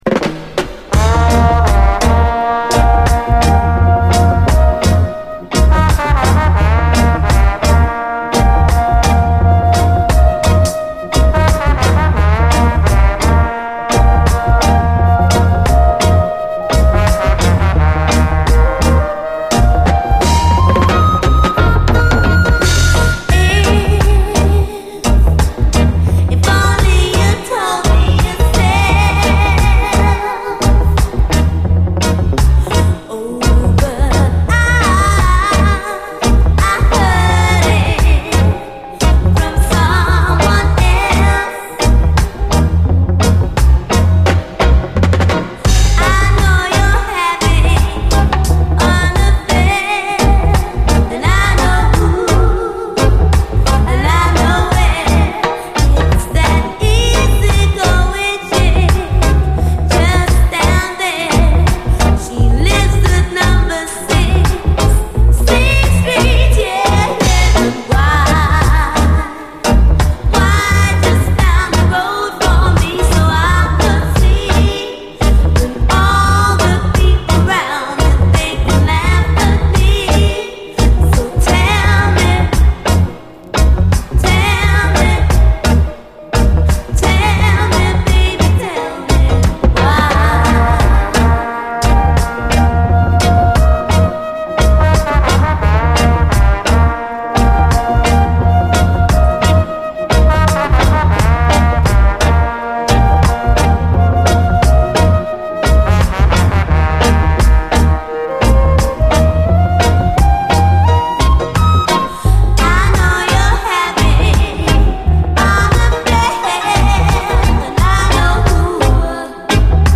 REGGAE
後半はダブに接続！